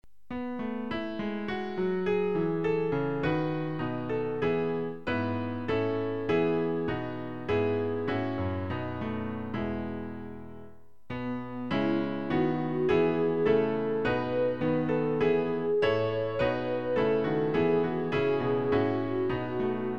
Klavier-Playback zur Begleitung der Gemeinde
(ohne Gesang)